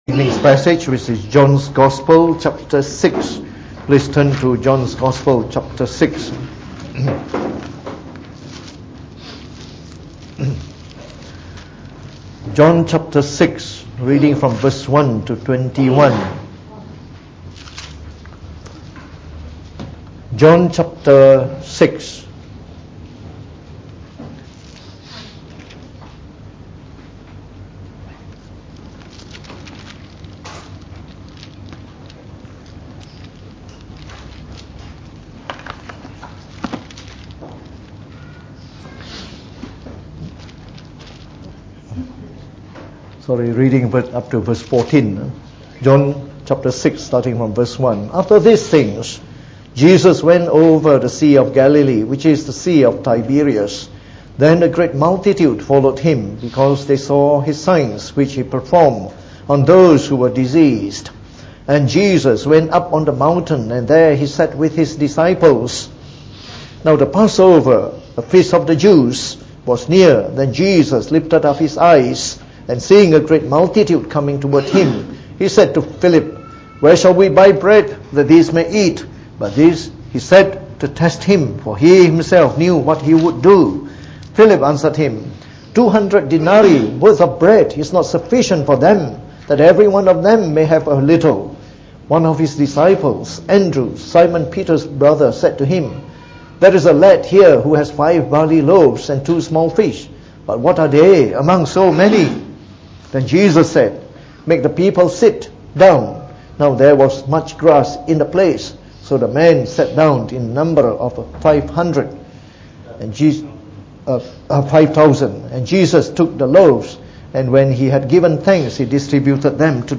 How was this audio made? Preached on the 14th October 2018.